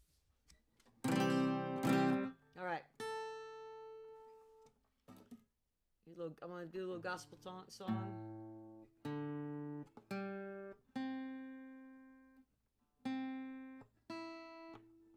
(audio from a portion of the program captured from webcast)
08. talking with the crowd (amy ray) (0:15)